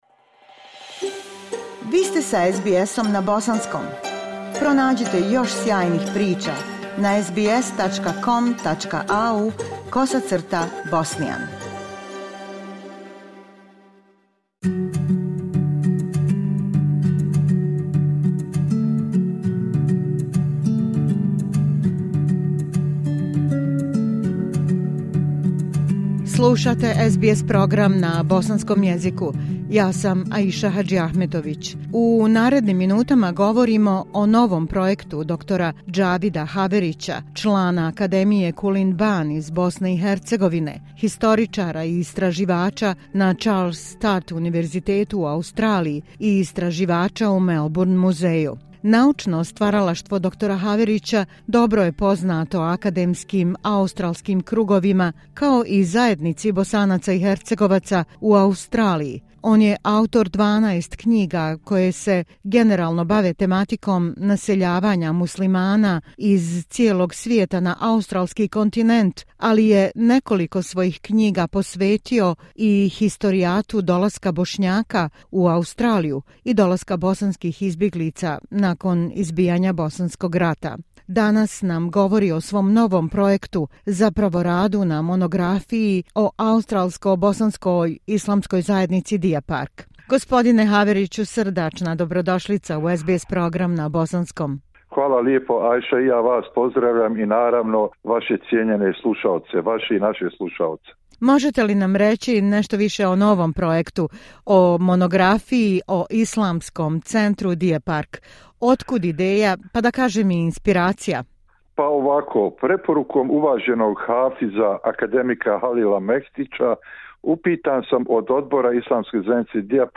U našem razgovoru otkrio je detalje svog novog projekta - monografiji o Australijsko- bosanskoj islamskoj zajednici "Deer Park", koja će ove godine obilježiti 50 godina postojanja.